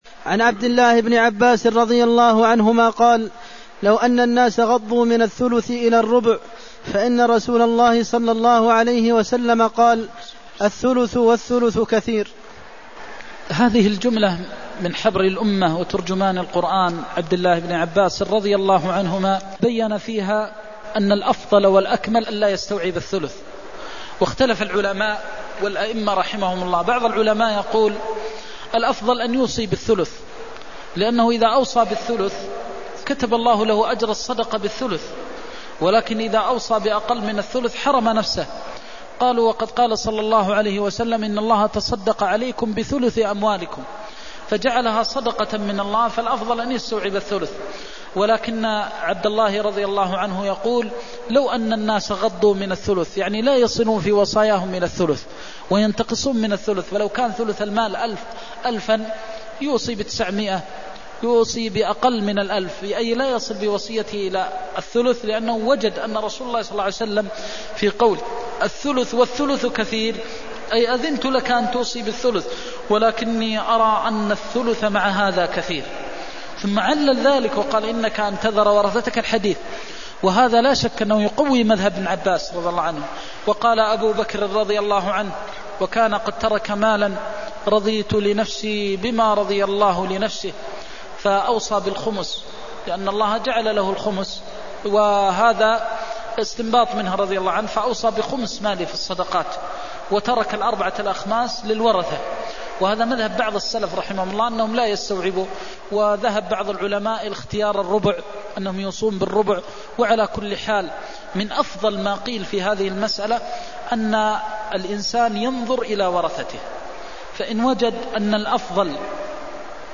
المكان: المسجد النبوي الشيخ: فضيلة الشيخ د. محمد بن محمد المختار فضيلة الشيخ د. محمد بن محمد المختار لو أن الناس غضوا من الثلث إلى الربع (280)